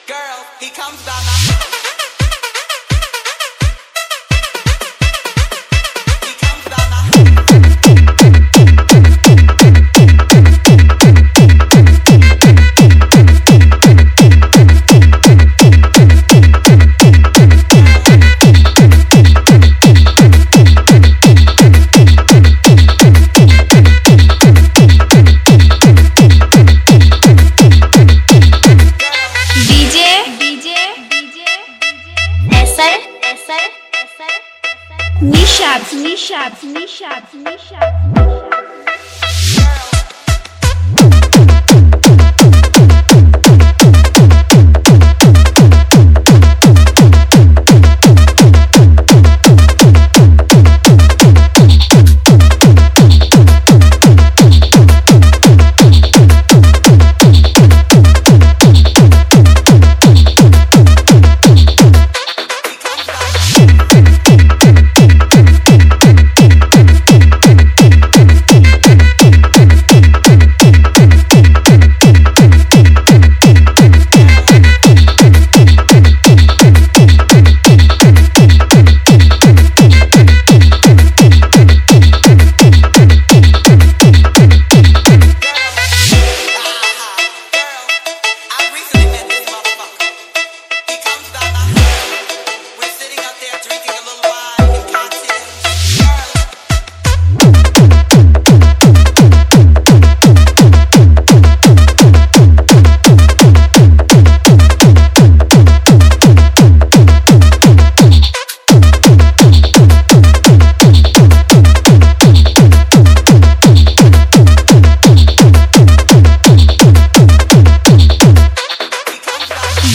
Category : Others Remix Songs